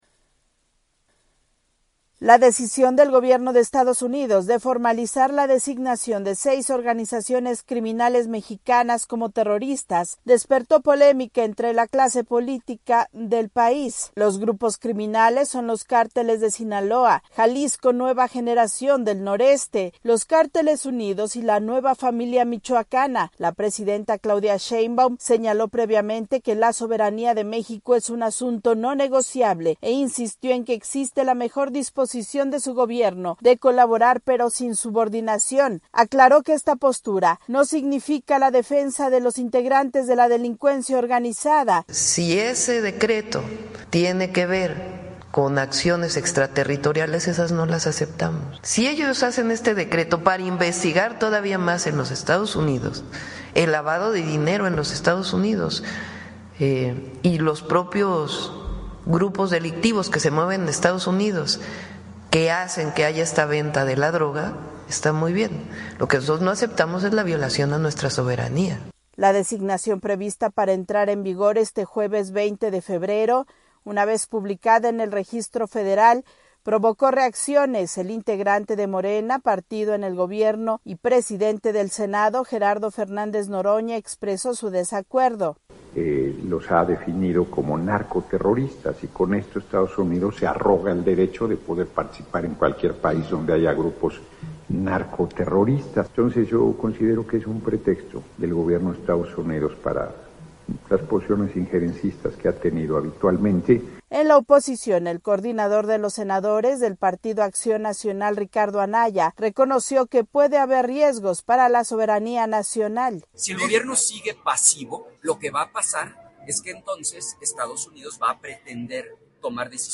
AudioNoticias
La presidenta mexicana Claudia Sheinbaum advirtió que no aceptará acciones extraterritoriales mientras Estados Unidos declaraba terroristas a cárteles mexicanos. Desde Ciudad de México informa la corresponsal de la Voz de América